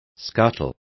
Complete with pronunciation of the translation of scuttles.